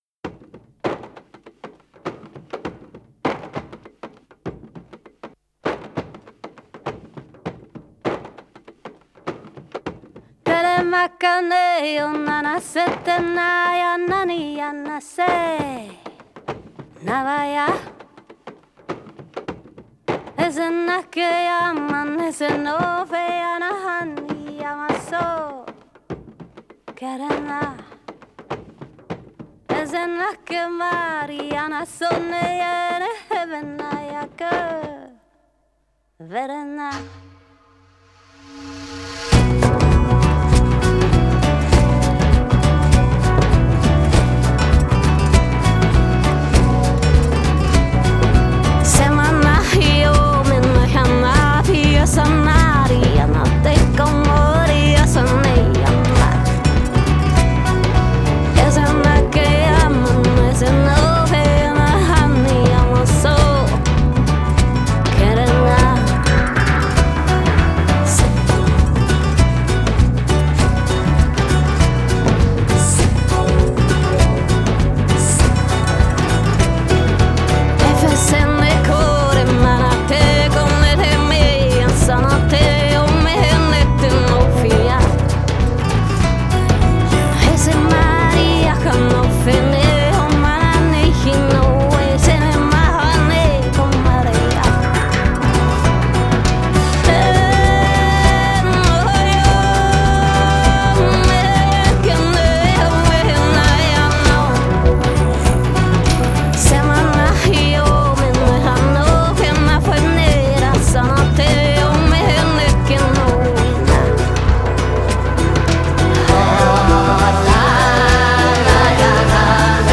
Style: Saami folk, Joik
Stereo